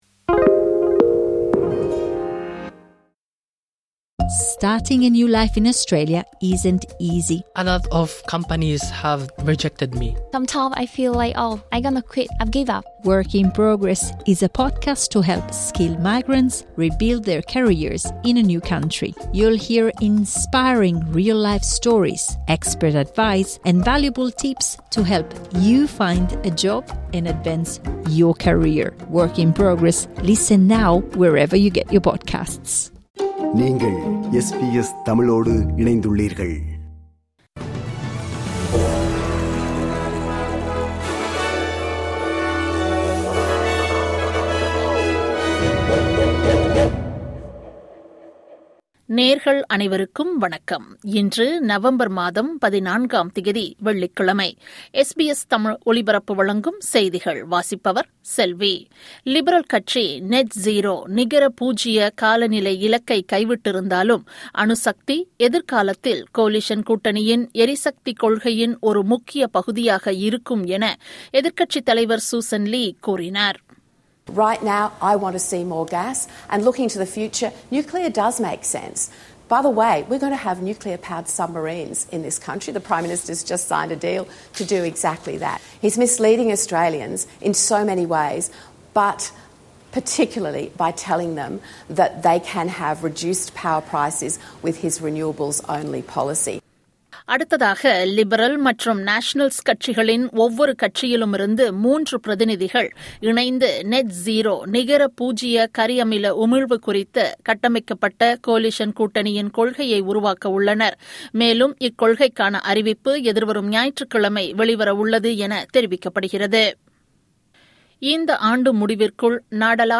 இன்றைய செய்திகள்: 14 நவம்பர் 2025 - வெள்ளிக்கிழமை
SBS தமிழ் ஒலிபரப்பின் இன்றைய (வெள்ளிக்கிழமை 14/11/2025) செய்திகள்.